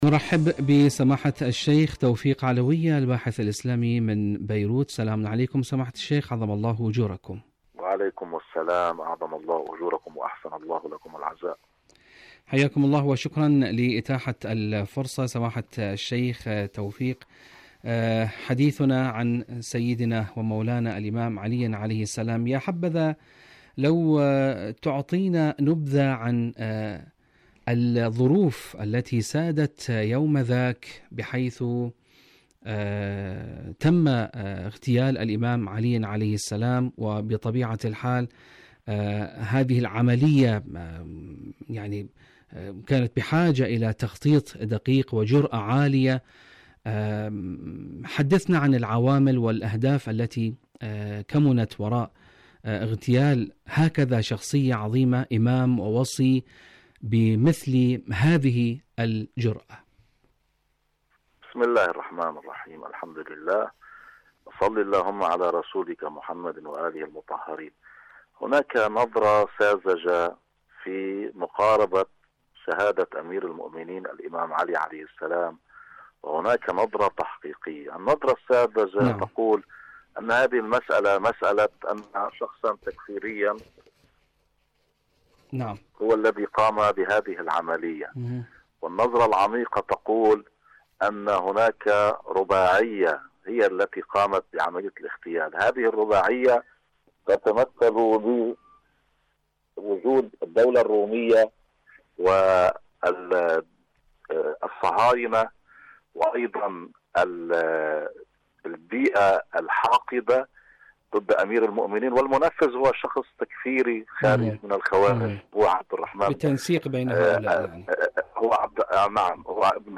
إذاعة طهران العربية مقابلات إذاعية برنامج الوصي الشهيد